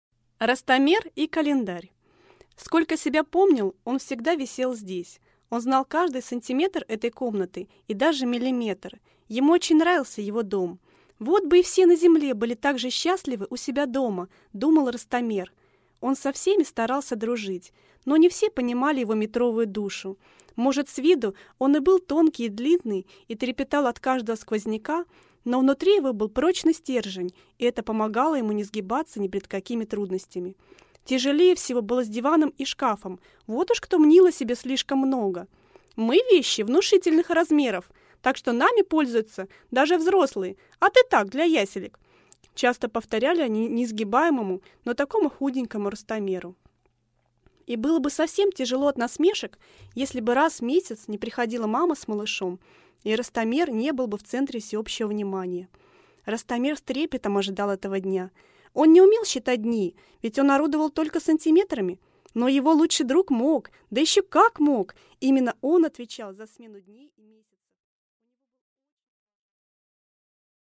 Аудиокнига Ростомер и календарь | Библиотека аудиокниг